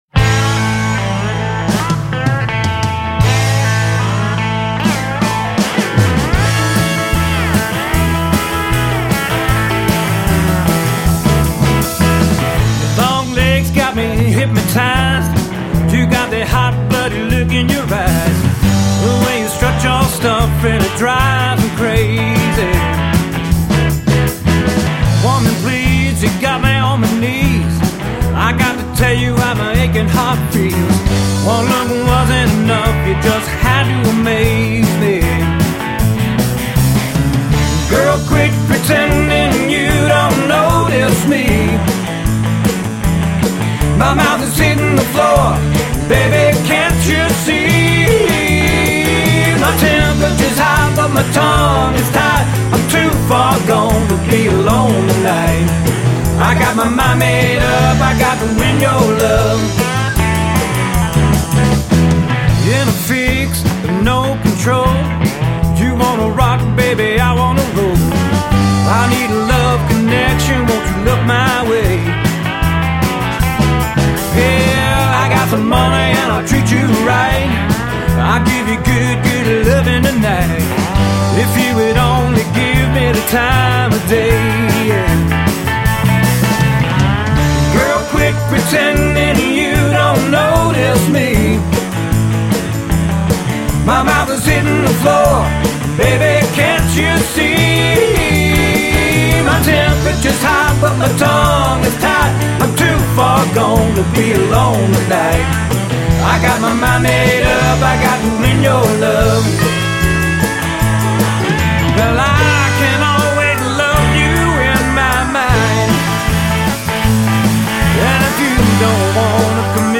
Original Mountain Rock From Cashiers